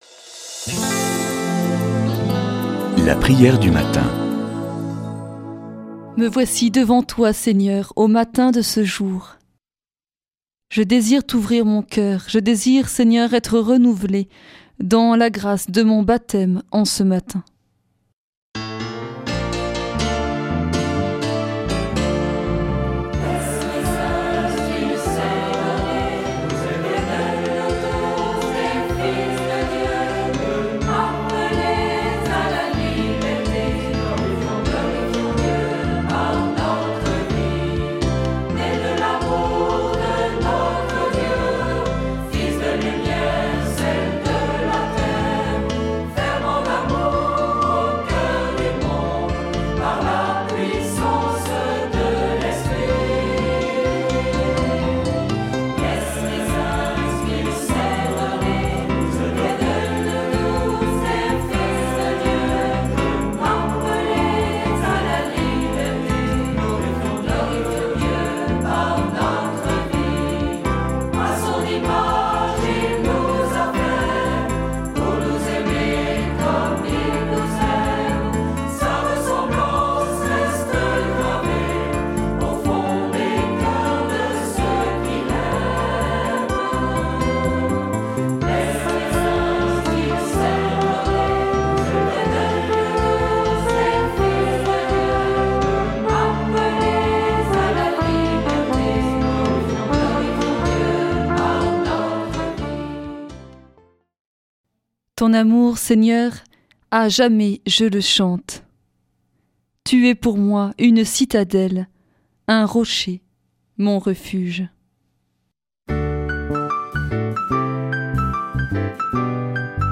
Prière du matin
Une émission présentée par Groupes de prière